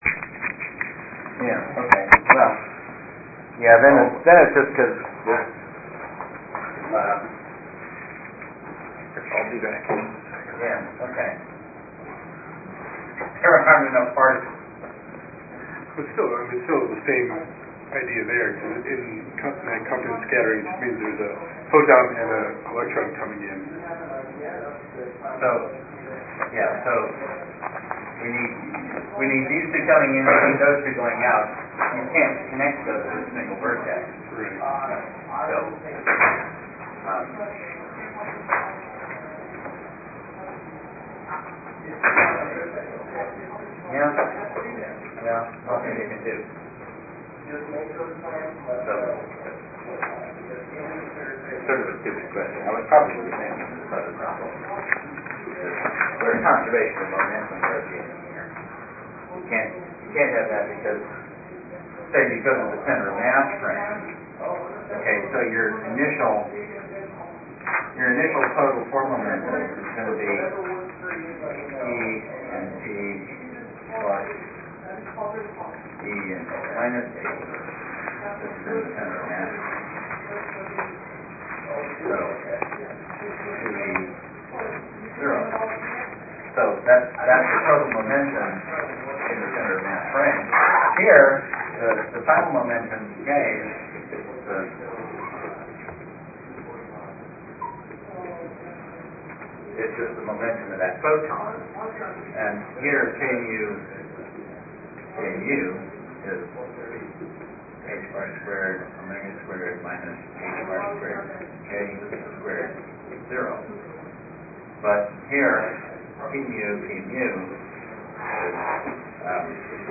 Field Theory Seminar
aHFTLecture.MP3